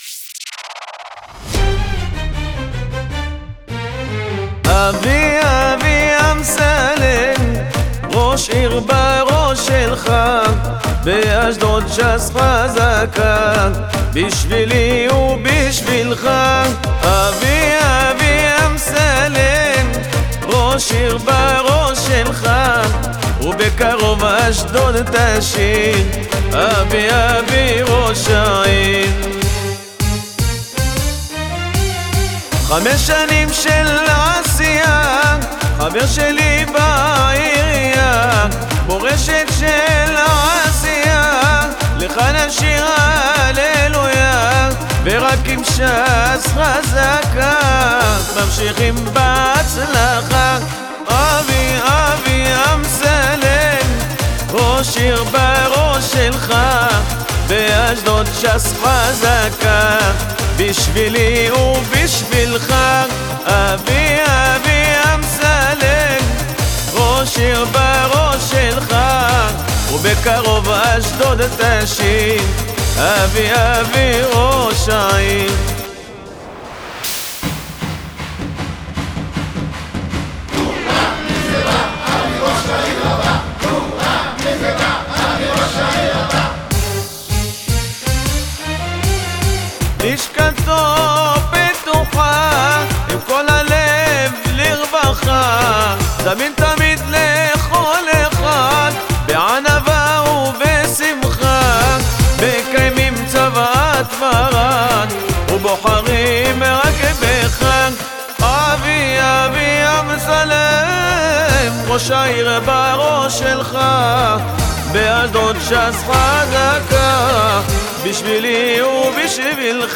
הג'ינגל החדש והמקפיץ